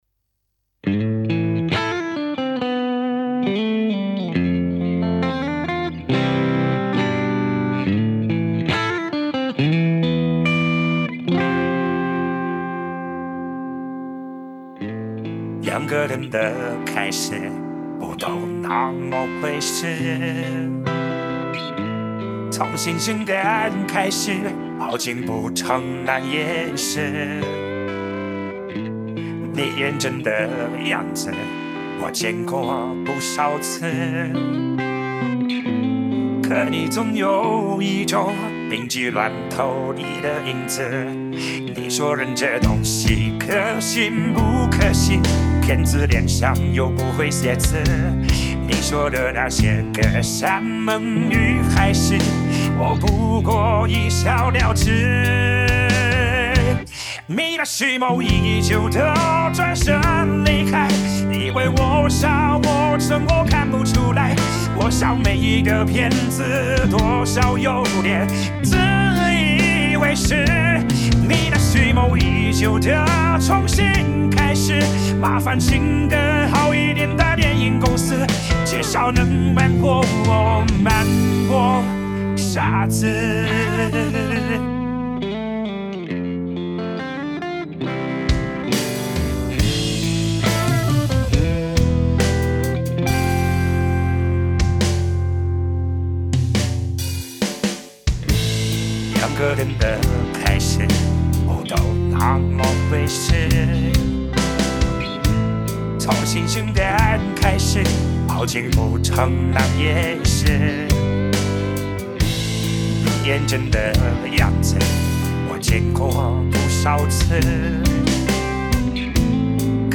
RVC模型 痞老板声音模型
推理效果试听